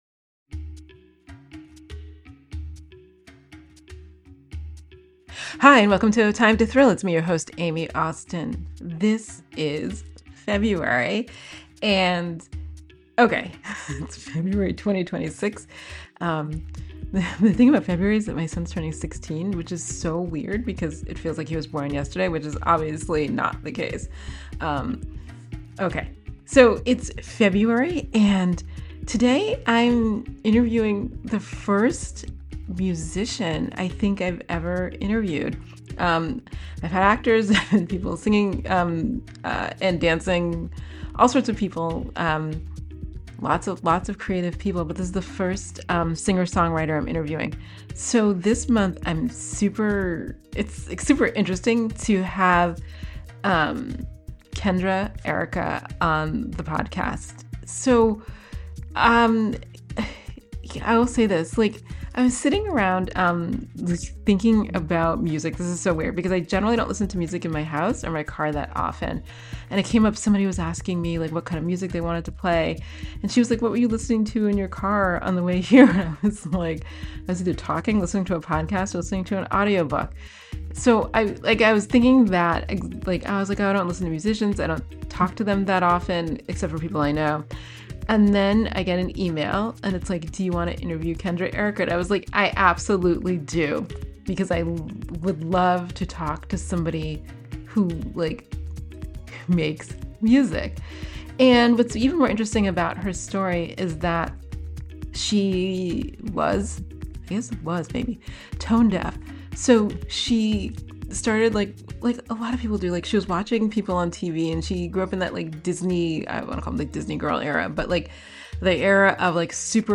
Episode 64: A Time to Thrill – Conversation